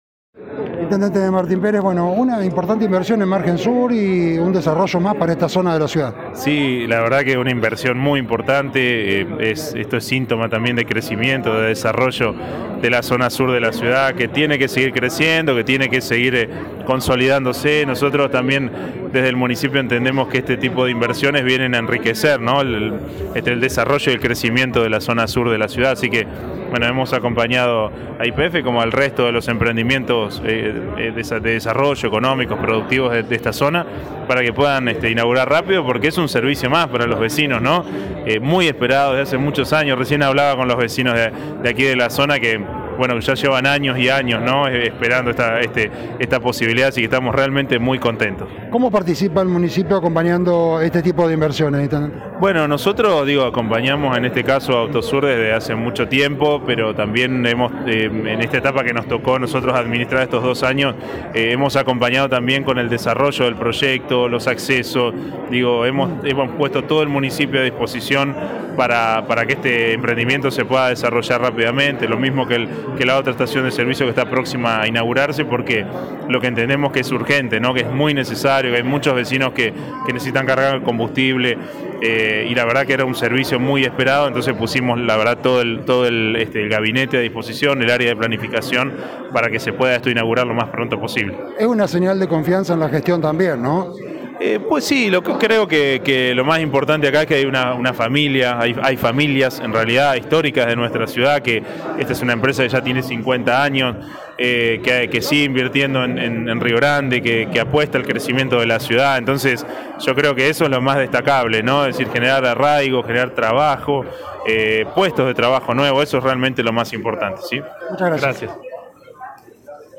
En dialogo con este medio el jefe comunal destaco la inversión privada el desarrollo y el crecimiento de la zona sur de la ciudad así que hemos acompañado a YPF como al resto de los emprendimientos de desarrollo económico de esta zona para que puedan inaugurar rápido porque es un servicio más para los vecinos, muy esperada desde hace muchos años.